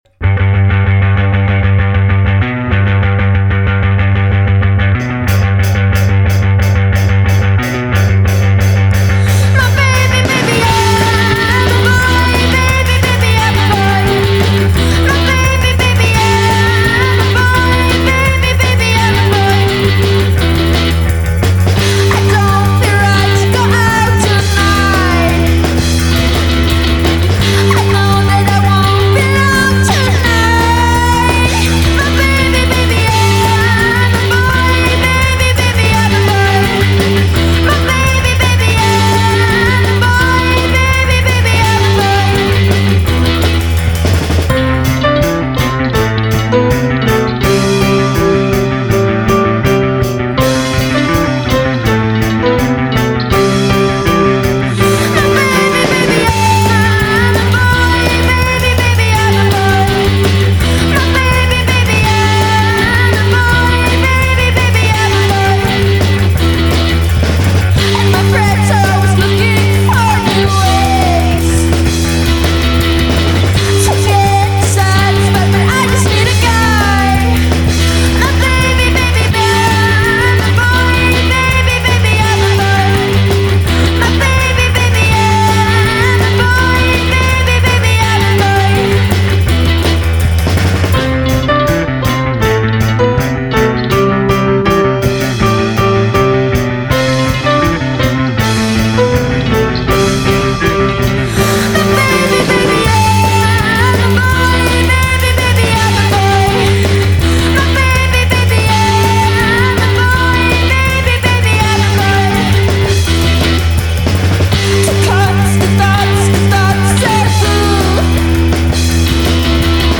with a soft Swedish 'G'